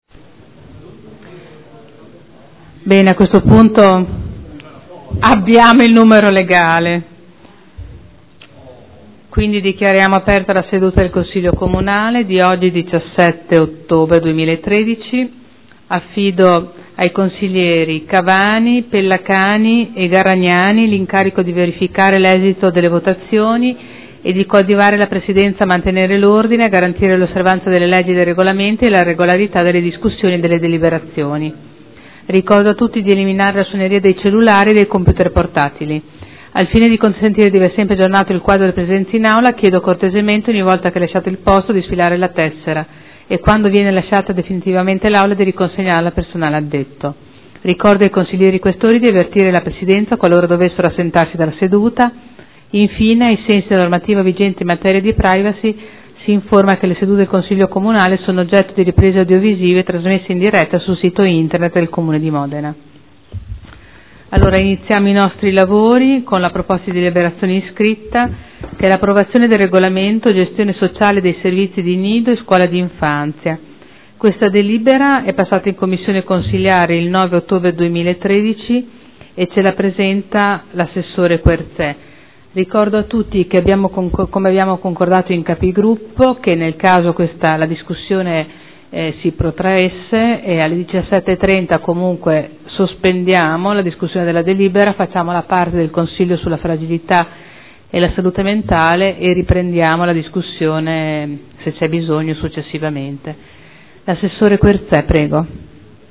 Seduta del 17/10/2013 Il Presidente apre i lavori del Consiglio Comunale.